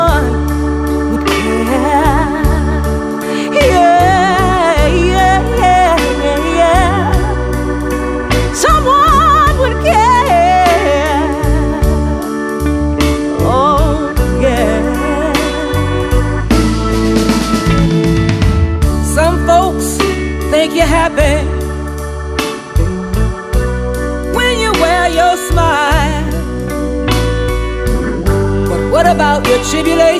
Musique du Monde